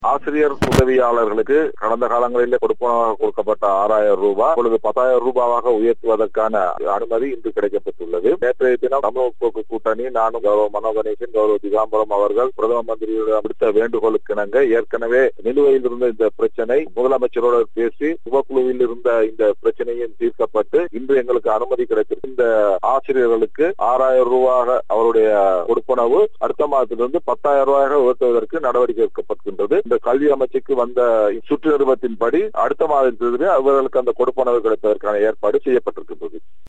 இது தொடர்பாக அவர் இவ்வாறு கருத்து தெரிவித்தார்.